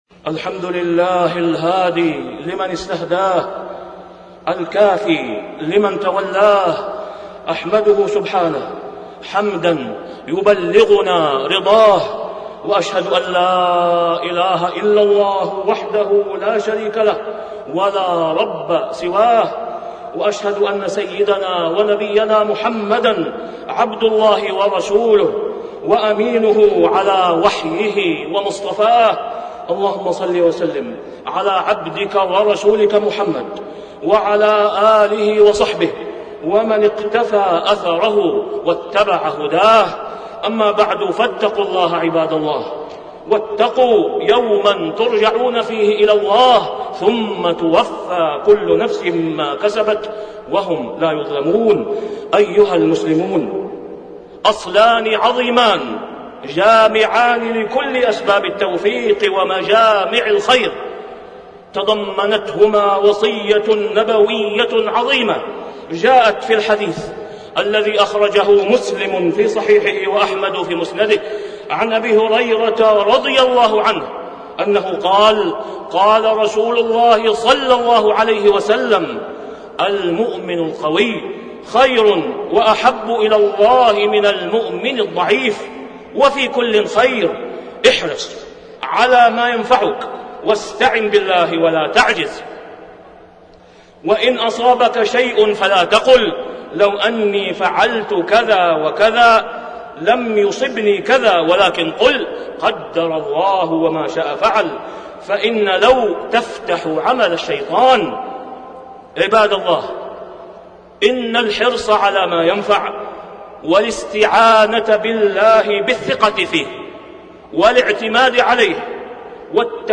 تاريخ النشر ١٣ شوال ١٤٣٣ هـ المكان: المسجد الحرام الشيخ: فضيلة الشيخ د. أسامة بن عبدالله خياط فضيلة الشيخ د. أسامة بن عبدالله خياط احرص على ما ينفعك